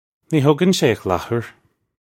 Pronunciation for how to say
Nee hoe-gun shay akh lahoor. (U)
This is an approximate phonetic pronunciation of the phrase.